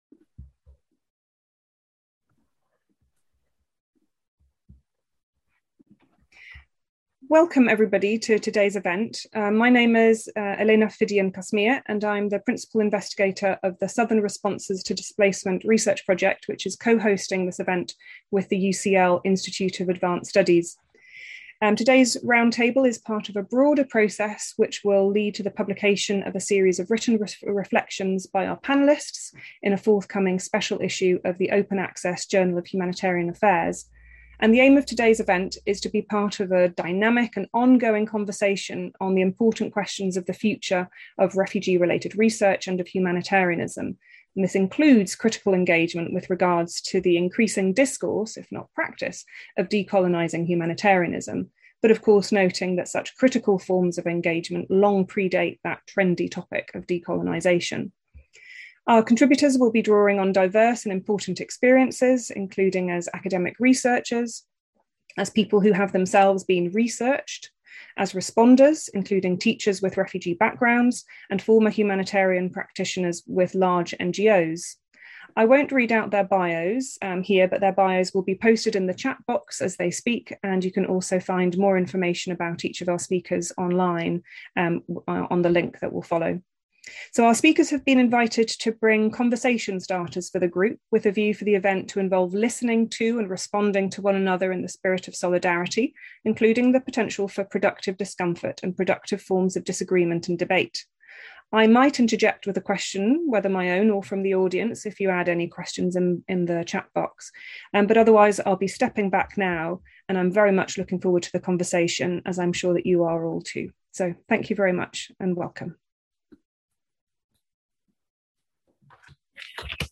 On the 4th of July 2022 the Southern Responses to Displacement project hosted a roundtable discussion on ‘Critical reflections on ‘Decolonising’ Humanitarianism and Refugee-Related Research.’ Speakers at the event drew on a range of historical, geographical and epistemological perspectives to collectively explore the future of humanitarian practice and research, including how to go beyond the fetishization of ‘decolonising’ humanitarianism and refugee-related research.